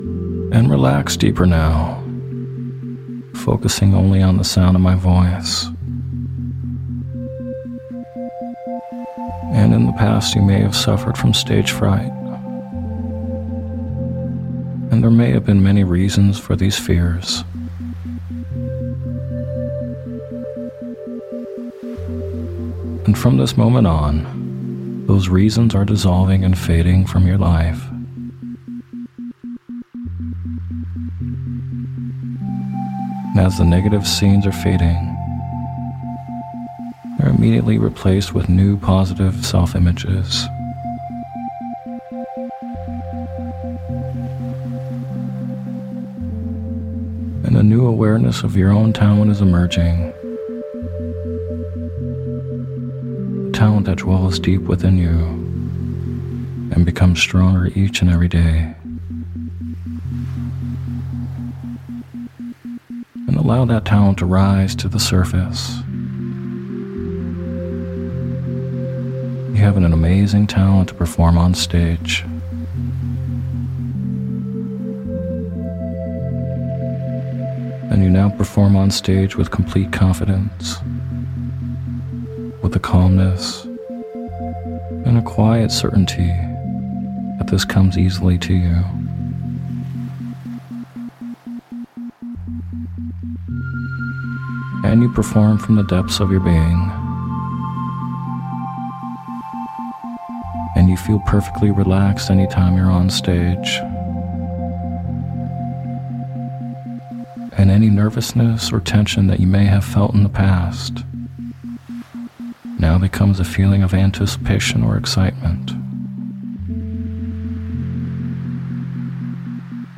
Sleep Hypnosis For Stage Fright With Isochronic Tones
In this guided meditation or sleep hypnosis audio you’ll be given positive suggestions and a visualization for getting over stage fright.